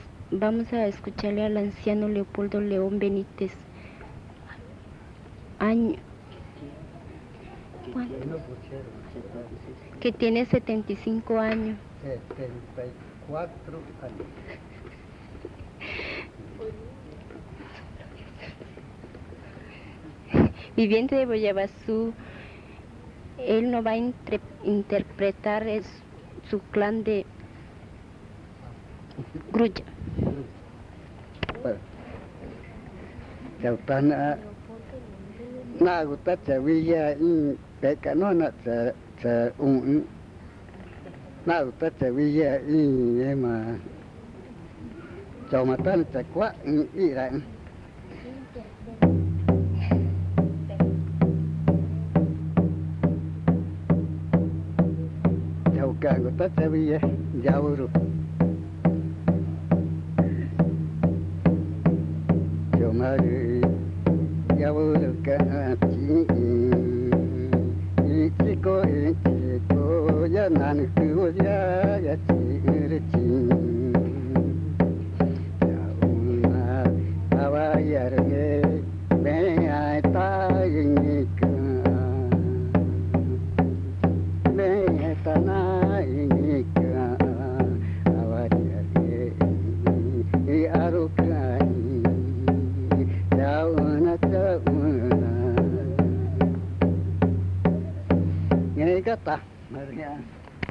Canto del clan Grulla
Pozo Redondo, Amazonas (Colombia)
El abuelo usa el tambor mientras canta.
The elder uses a drum while singing.